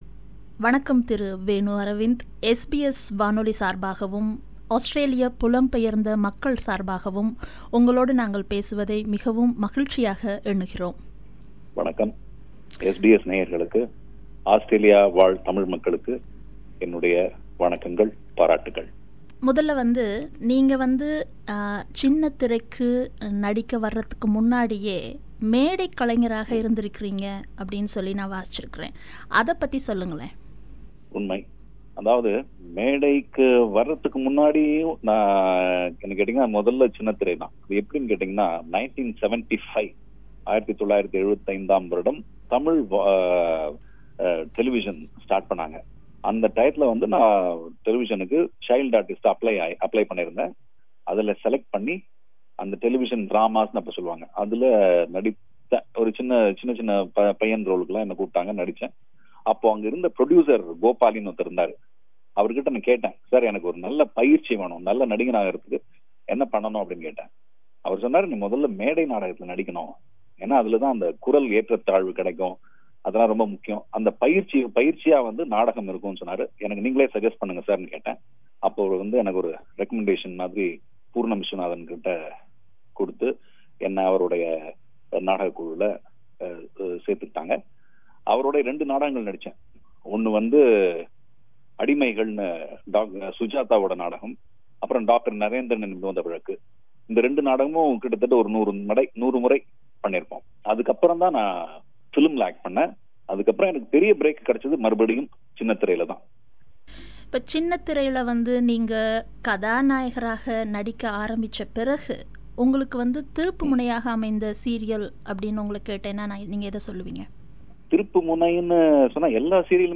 He shares his experience with us and he is interviewed